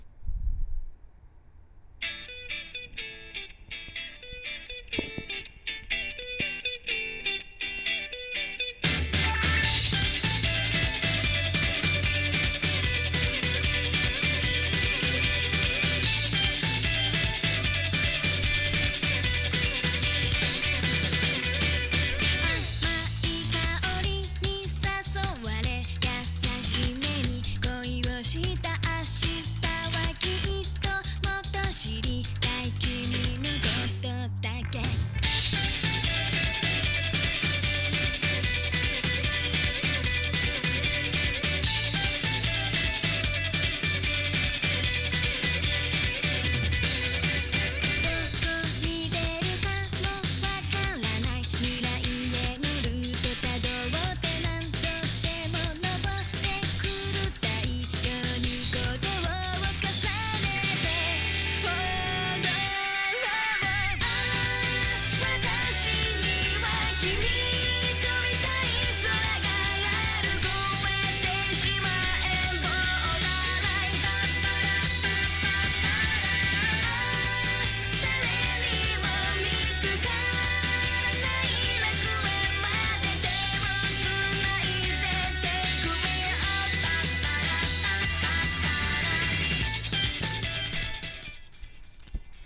・ステレオICレコーダー：オリンパス LS-20M リニアPCM 44.1kHz/16bit 非圧縮WAV形式
audio-technicaは全体的な音のバランスが良く、各楽器も分離して綺麗に聴こえます。
さらに外部DACアンプにすると音の品位が向上して、ずっと聴いていたい音になると思います。